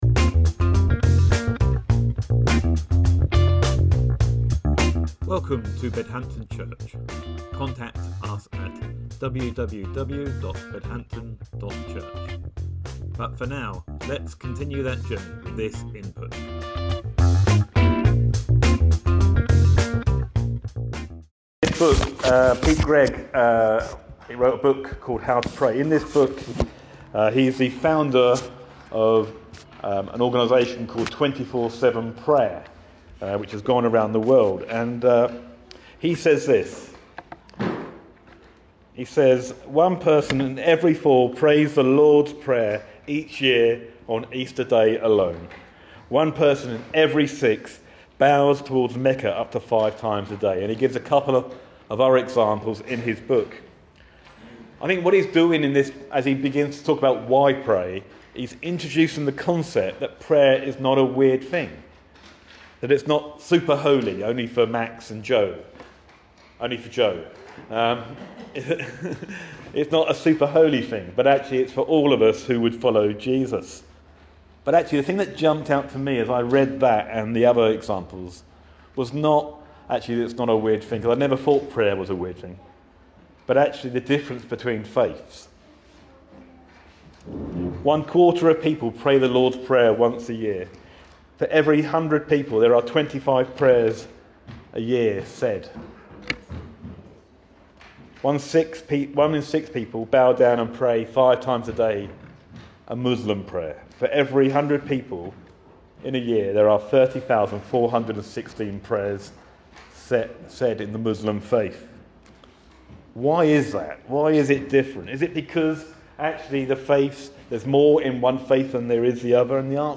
Sermon July 28th, 2024 - Watch and Pray - Bedhampton Church